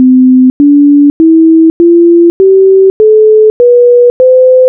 다음 MP3를 play하면 파이썬으로 만든 도레미파솔라시도를 들을 수 있어요.
pythagorean_scale.wav
# Base frequency for C4 (Do) in Pythagorean tuning
# Pythagorean ratios for C major scale (Do-Re-Mi-Fa-Sol-La-Ti-Do)
silence_duration = 0.1 # seconds of silence between notes